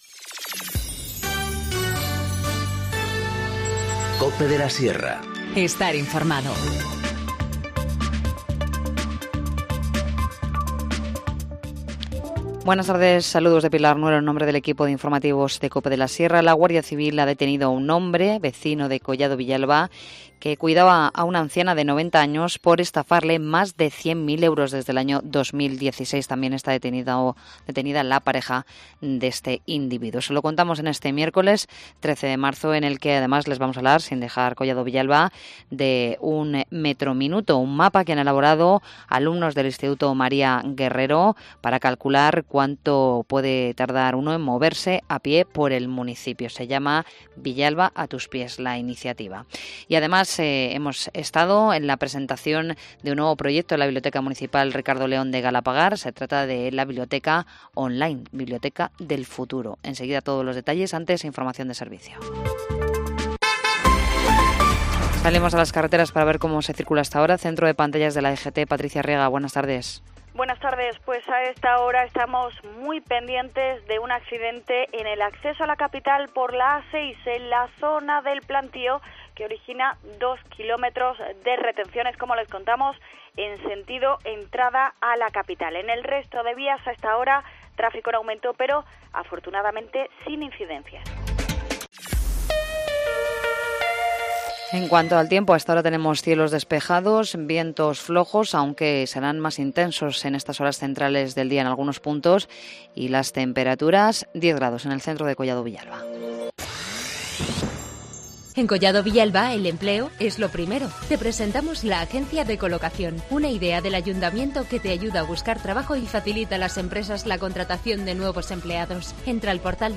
Informativo Mediodía 13 marzo-14:20h